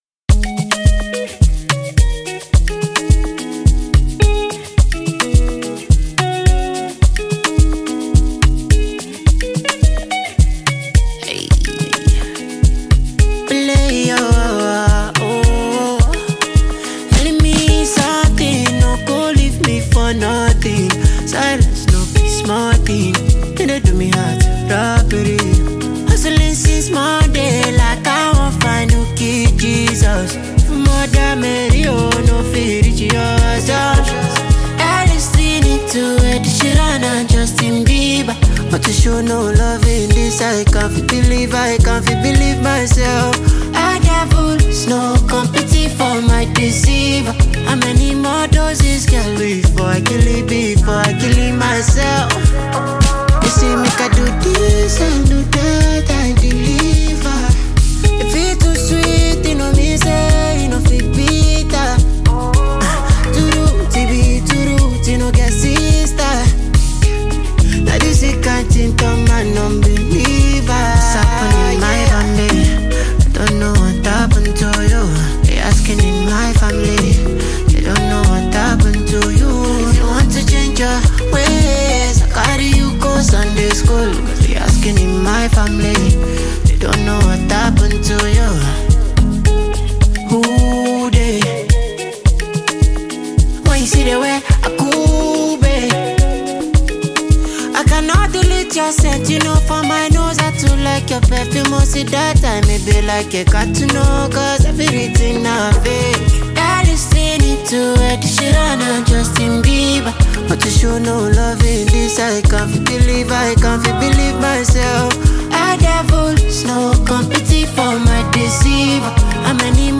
Nigerian / African Music
Genre: Afrobeats